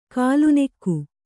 ♪ kālu nekku